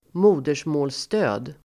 Uttal: [²m'o:der_små:l]